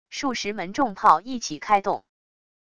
数十门重炮一起开动wav音频